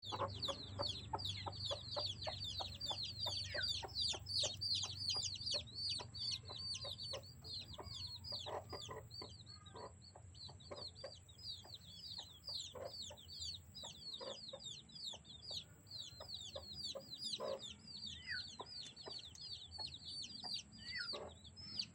Chick Téléchargement d'Effet Sonore
Chick Bouton sonore